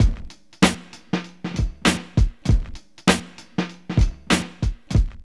BUSYSWING 98.wav